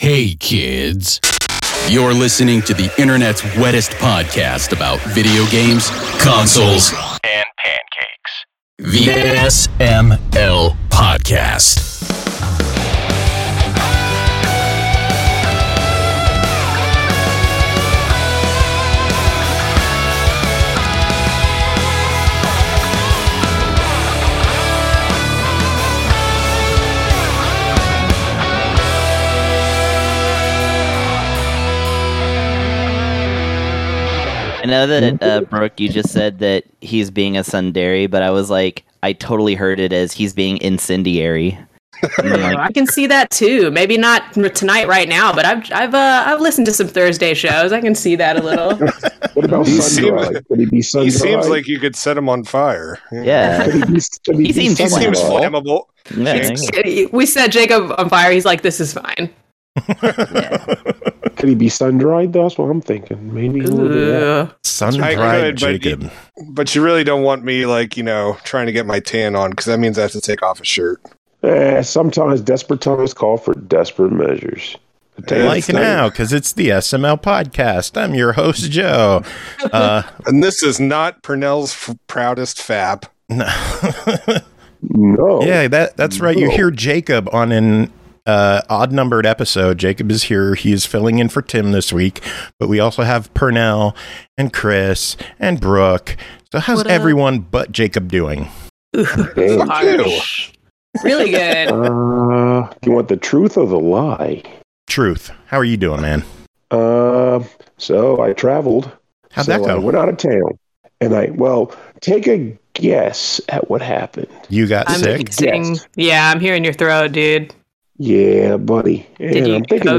The show ends with some Pokémon muzak